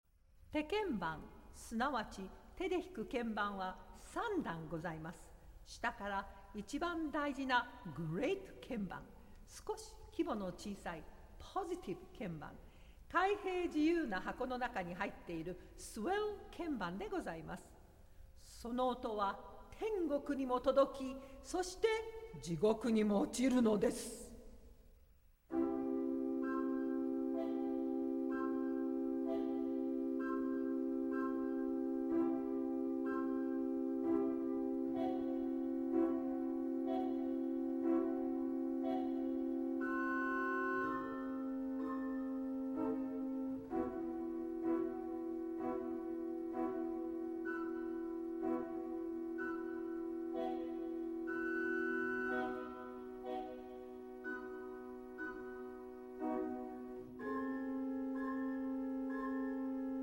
organ
percussion
Japanese narrator